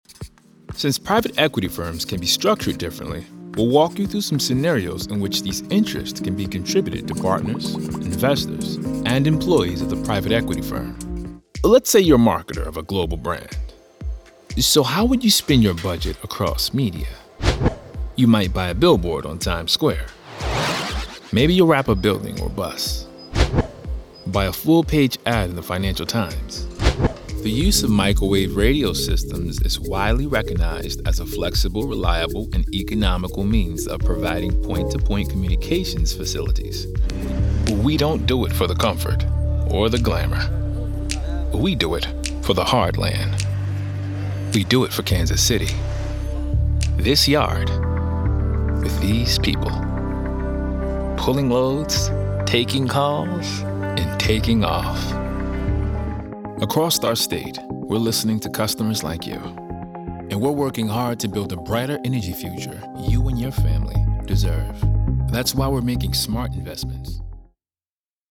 Full-time VO talent offering a home studio and quick project turnaround.
CORPORATE-NARRATION-DEMO
Young Adult
Middle Aged
CORPORATE-NARRATION-DEMO.mp3